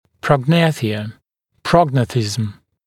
[ˌprɔg’næθɪə] [‘prɔgˌnæθɪzəm] [ˌprɔg’næθɪzəm][ˌпрог’нэсиэ] [‘прогˌнэсизэм] [ˌпрог’нэсизэм]прогнатия, прогения